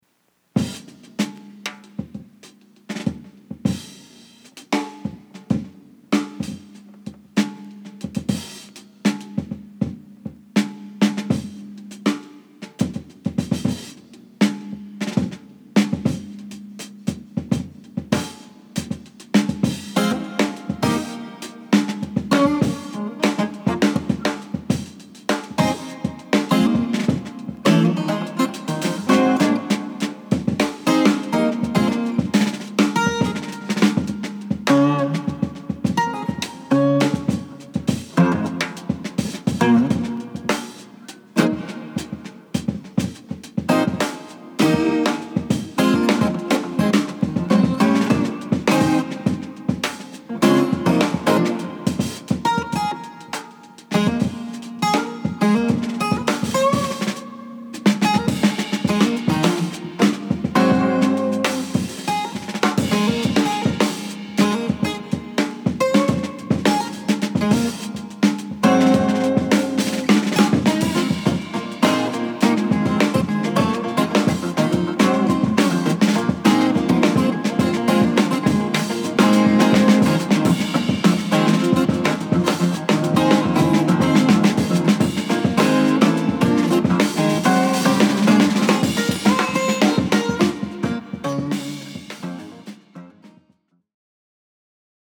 ドラムブレイク DRUM BREAK 前衛アグレッシヴ・ジャズ・ロック 試聴
ドラムブレイクで始まるクールなロックテイストの